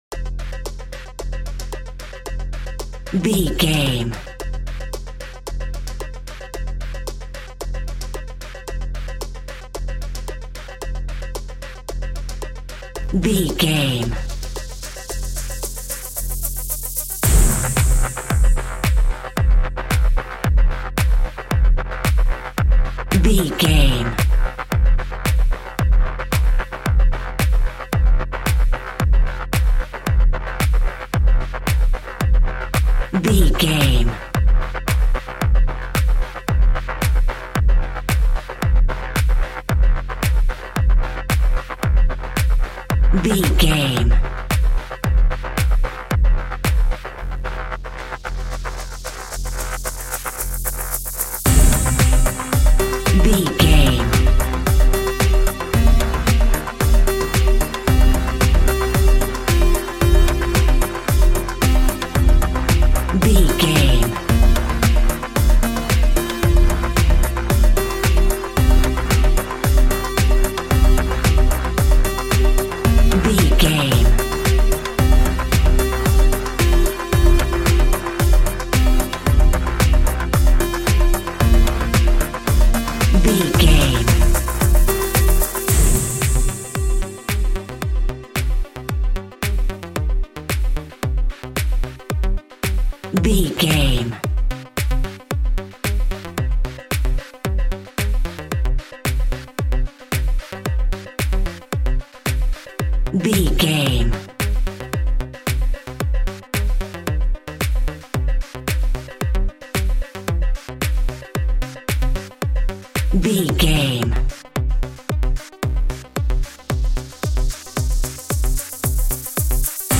Aeolian/Minor
groovy
uplifting
futuristic
driving
energetic
repetitive
synthesiser
drum machine
house
electro dance
synth leads
synth bass
upbeat